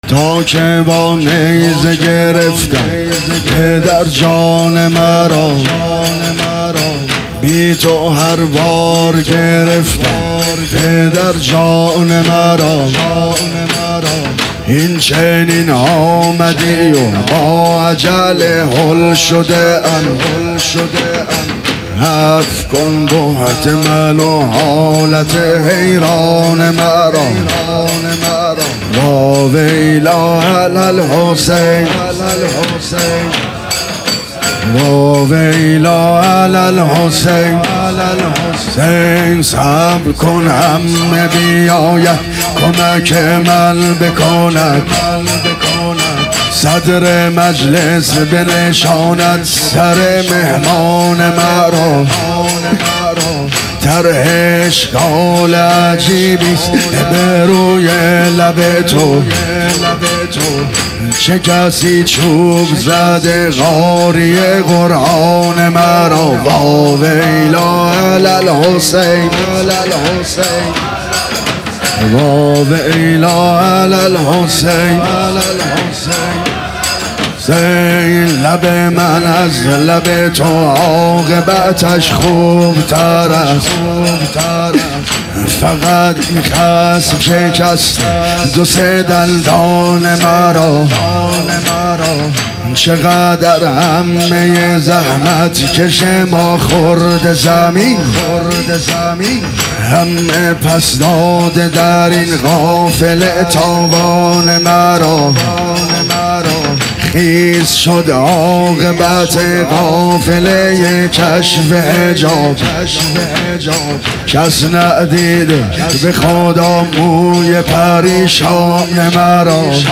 دمام زنی
محرم 1400 | حسینیه کربلا تهران